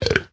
burp.ogg